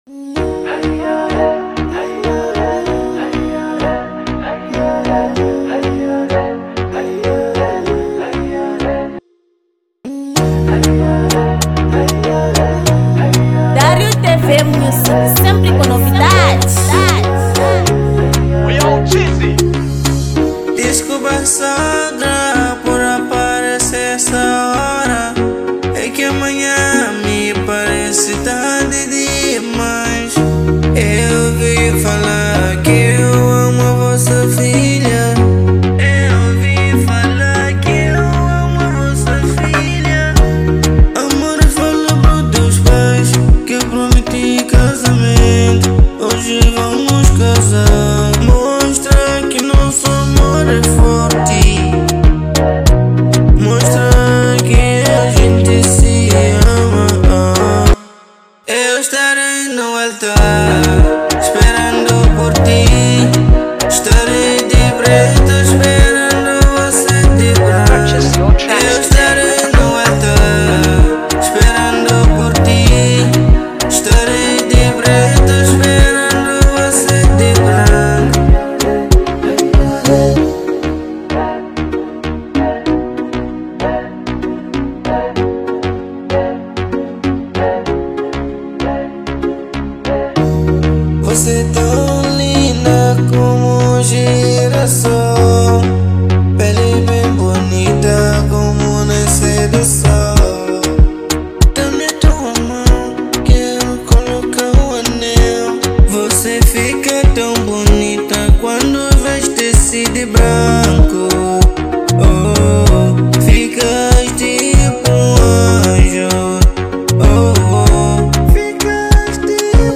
| Kizomba